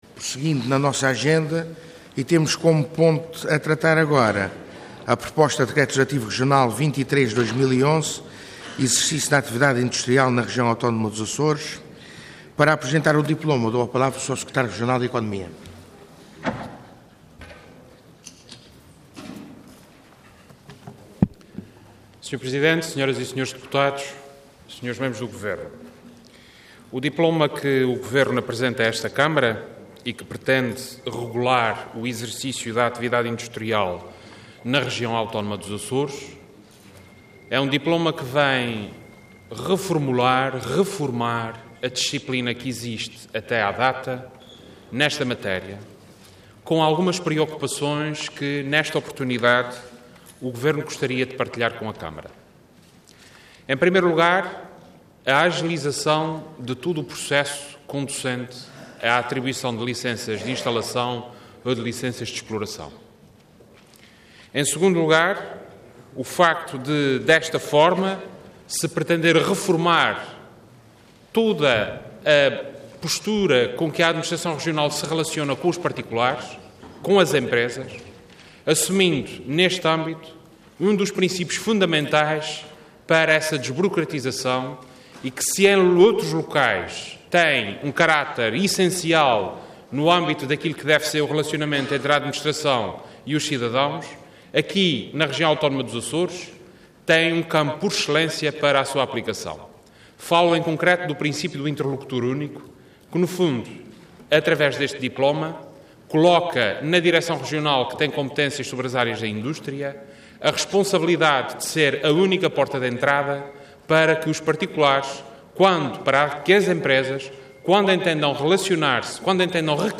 Detalhe de vídeo 15 de dezembro de 2011 Download áudio Download vídeo Diário da Sessão Processo IX Legislatura Exercício da actividade industrial na Região Autónoma dos Açores. Intervenção Proposta de Decreto Leg. Orador Vasco Cordeiro Cargo Secretário Regional da Economia Entidade Governo